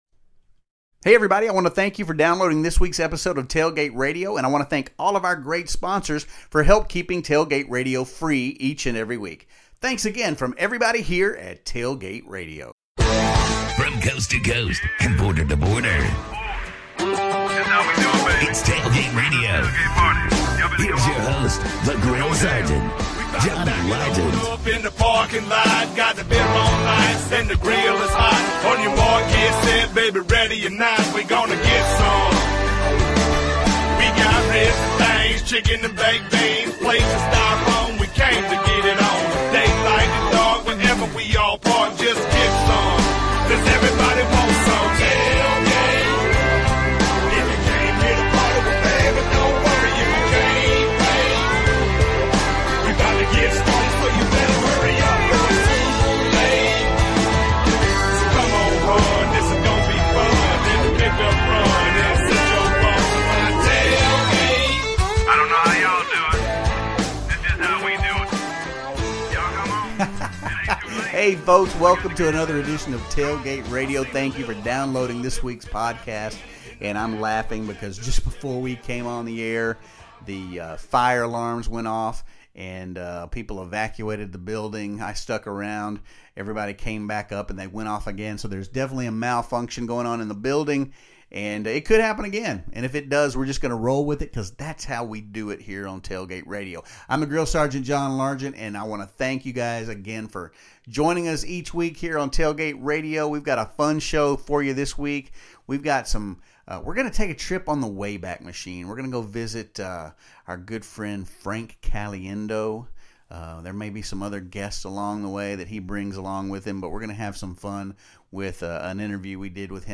Listen as Frank brings John Madden, Al Pacino, Jim Rhome and others to life with his spot on impressions!